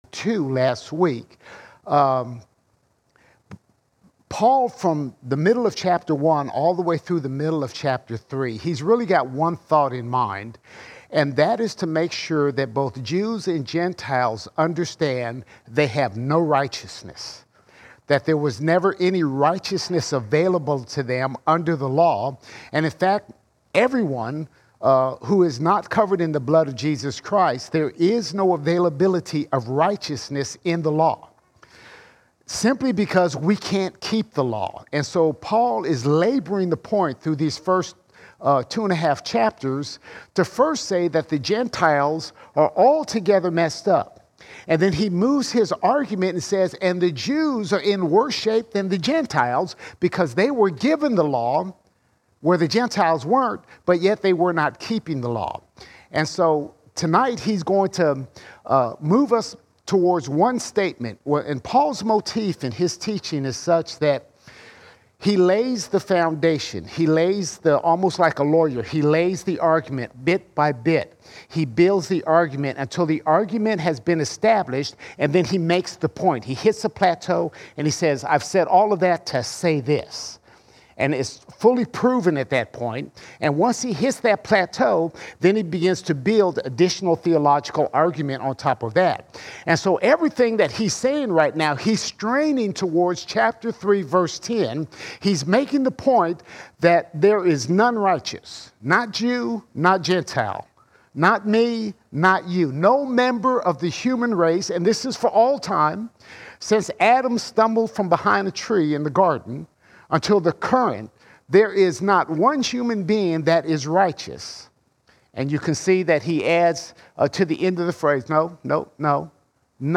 20 July 2023 Series: Romans All Sermons Romans 2:21 to 3:20 Romans 2:21 to 3:20 Paul explains that no man, no one ever, is righteous by God's standard.